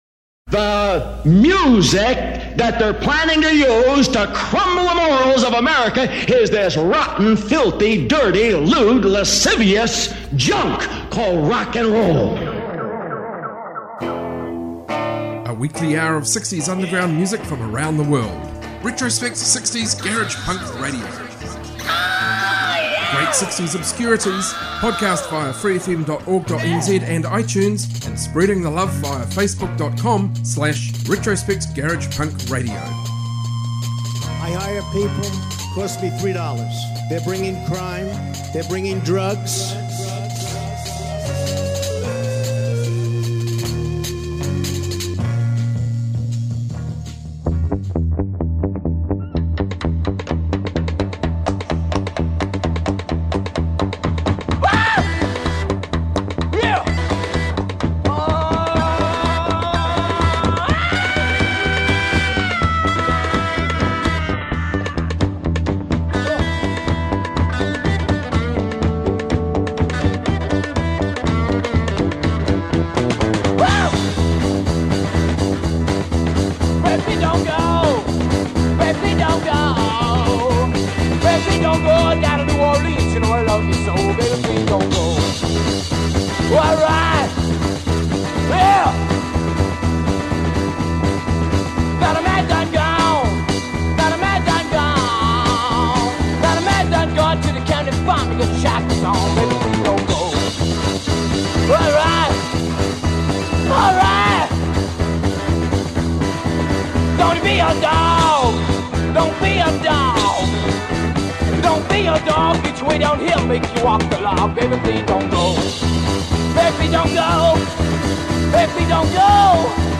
Sixties Garage Punk, Proto Punk, Garage rock, Freakbeat and Psychedelia